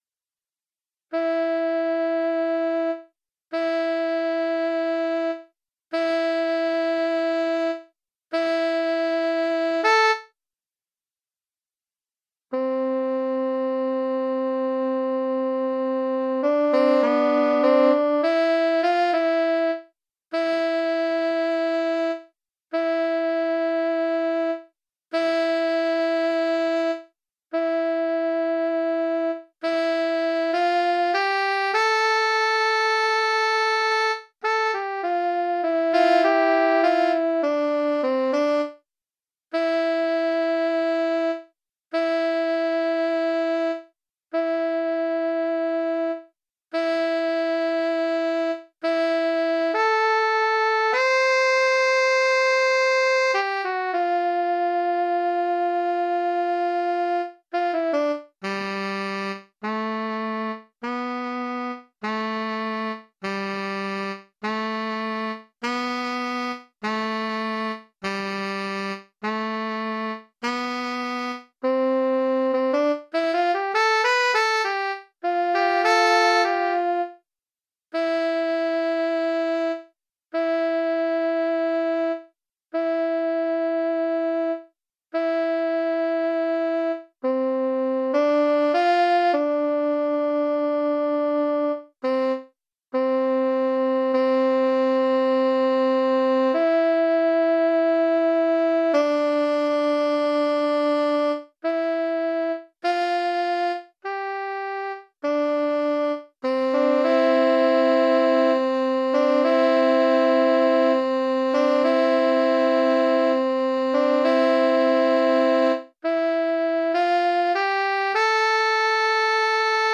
just scanned the pdf into Sibelius 6 software
The tenor study can be played.
The result gives some idea of the Tenor sax part. (file9)